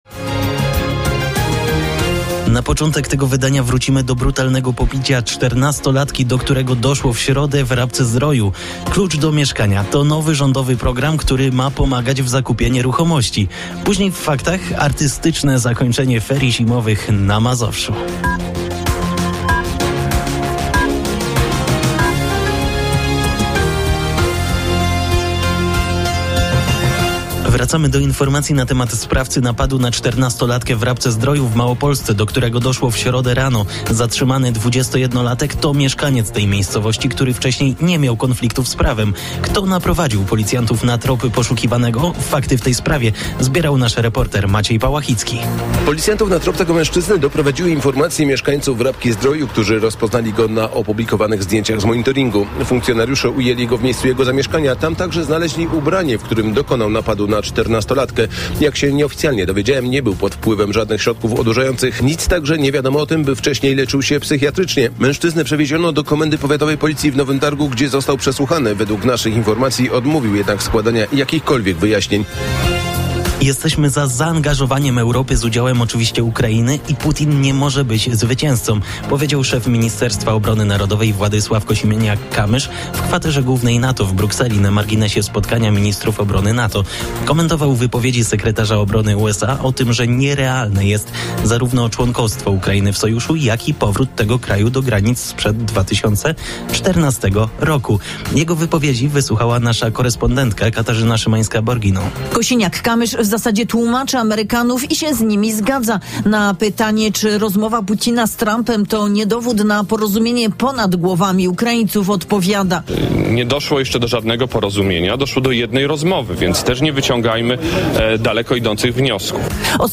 18:00 Fakty i Popołudniowa rozmowa w RMF FM - 01.10.2024